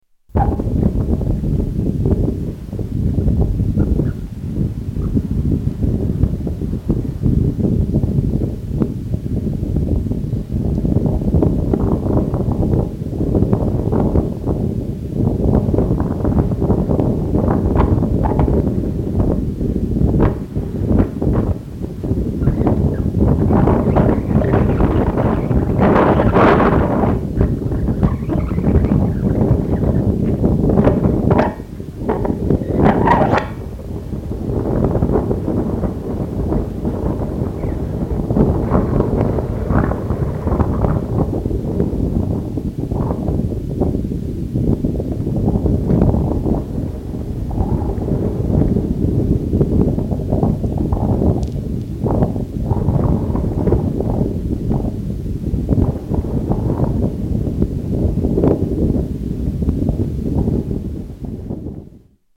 Salinas Grandes Salt Mine
Category: Travel/Int'l   Right: Personal